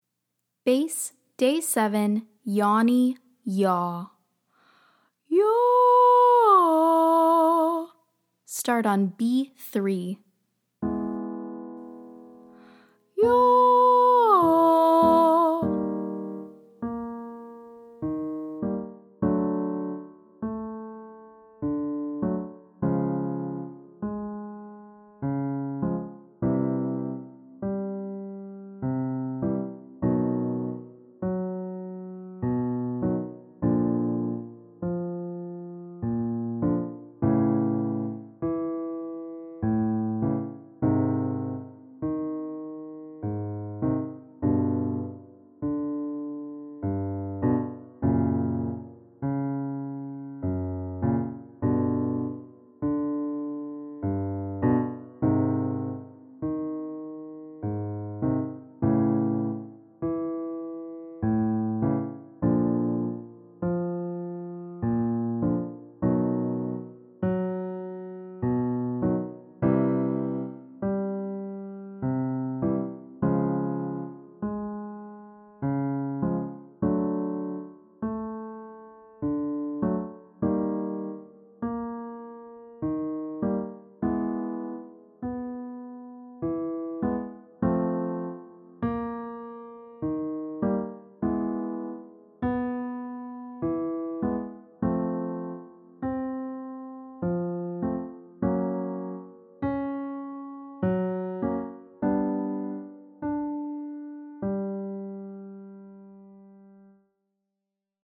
Day 7 - Bass - Yawny YAH